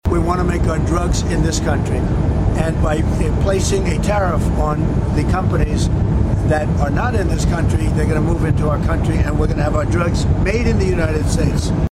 SPEAKING TO REPORTERS ABOARD AIR FORCE ONE, THE PRESIDENT SAID ONE OF THOSE GOODS WILL BE PRESCRIPTION DRUGS….